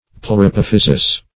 Search Result for " pleurapophysis" : The Collaborative International Dictionary of English v.0.48: Pleurapophysis \Pleu`ra*poph"y*sis\, n.; pl.